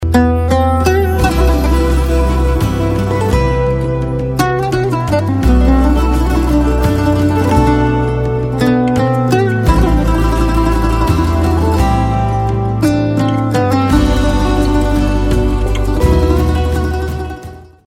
رینگتون ملایم و عاشقانه
(بی کلام)